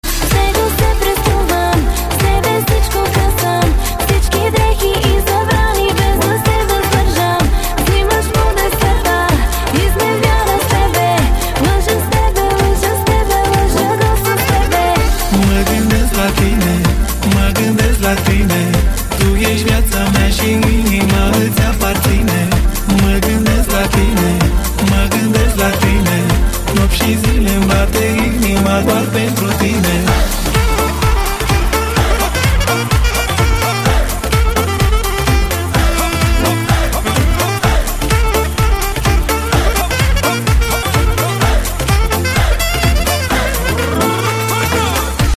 • Качество: 128, Stereo
мужской вокал
красивые
женский вокал
спокойные